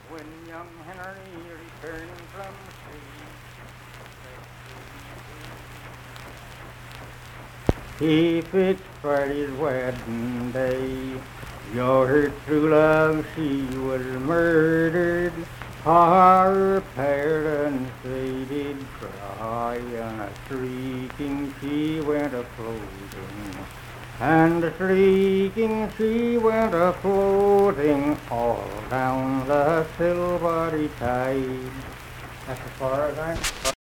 Unaccompanied vocal and fiddle music
Verse-refrain 1(7).
Voice (sung)
Pleasants County (W. Va.), Saint Marys (W. Va.)